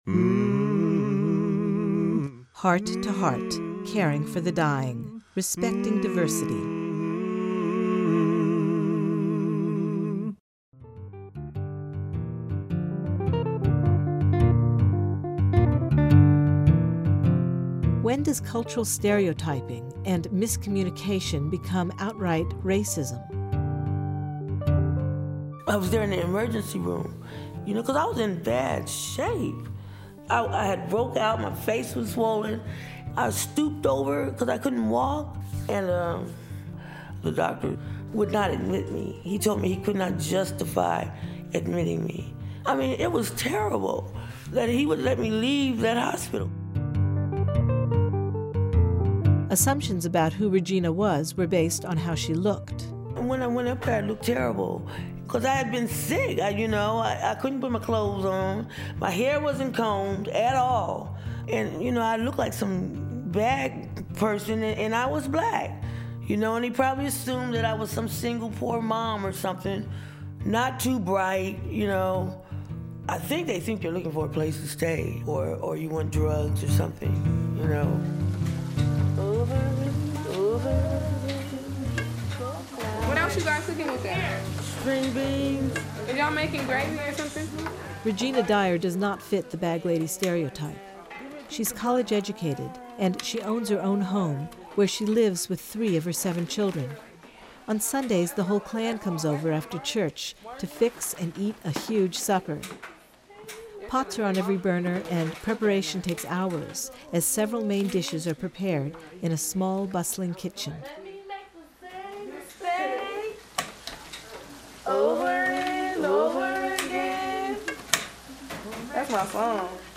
Heart-to-Heart Audio Documentary - Caring for the Dying